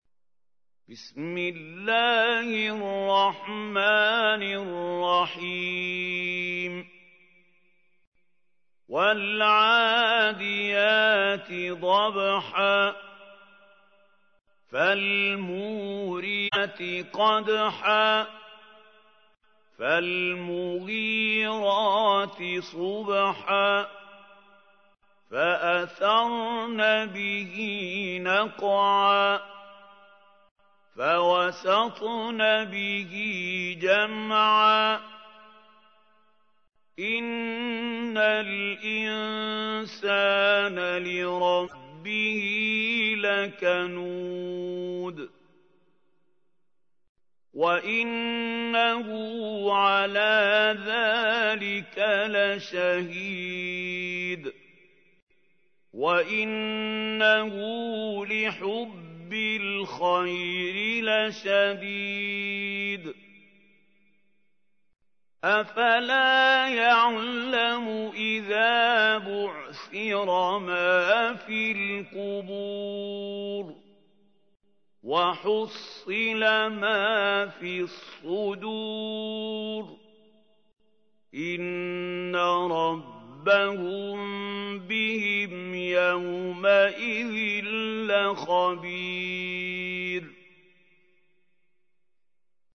تحميل : 100. سورة العاديات / القارئ محمود خليل الحصري / القرآن الكريم / موقع يا حسين